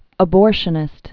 (ə-bôrshə-nĭst)